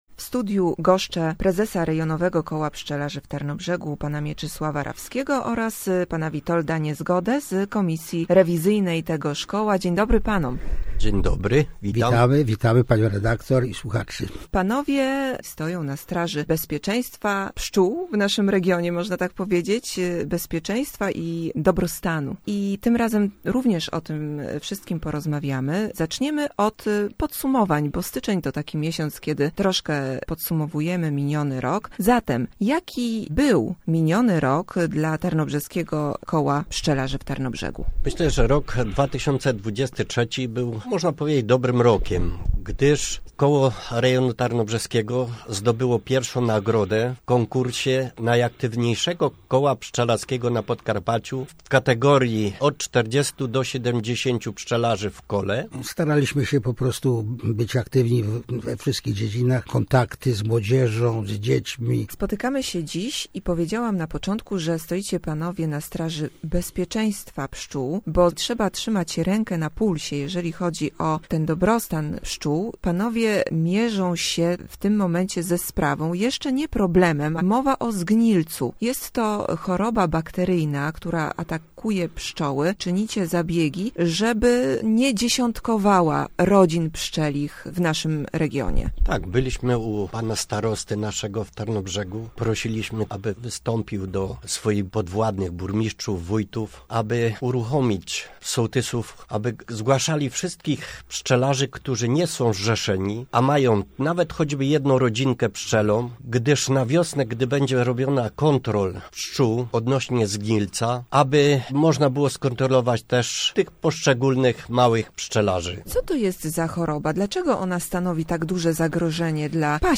Rozmowa z przedstawicielami Rejonowego Koła Pszczelarzy w Tarnobrzegu